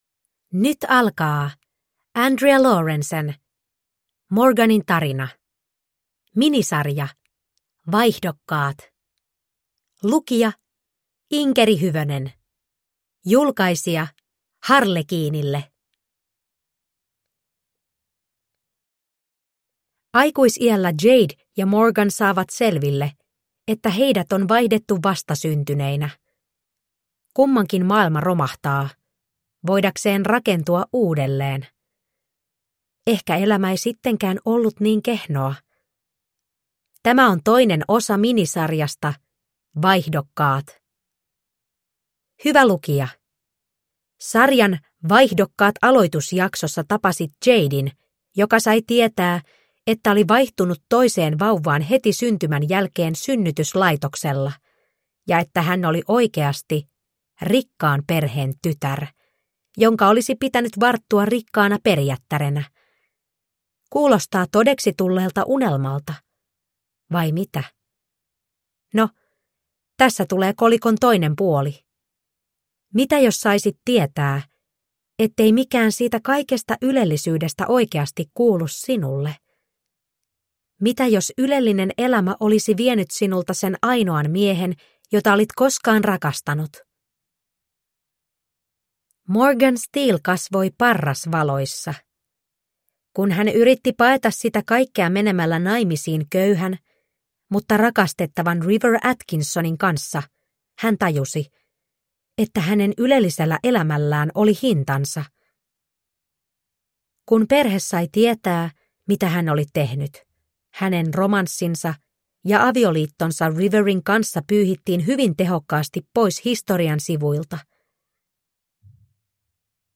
Morganin tarina (ljudbok) av Andrea Laurence